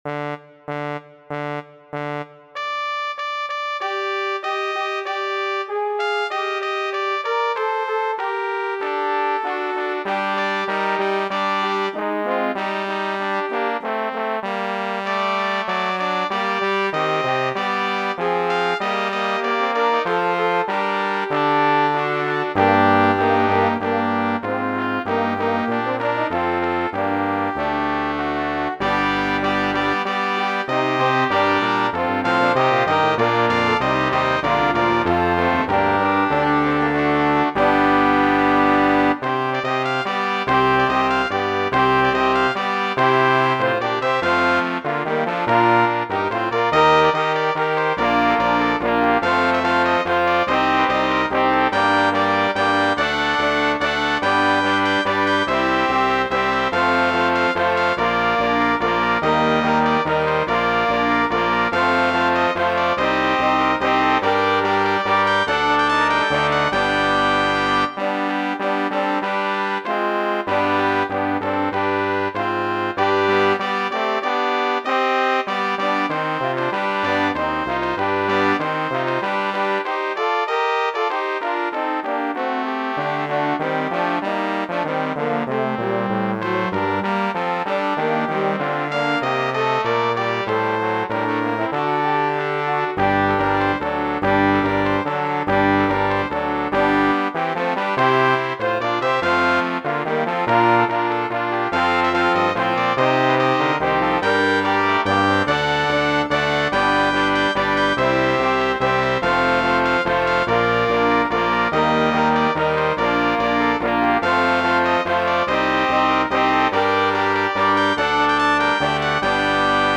Acht Stimmen in zwei Chören      nach oben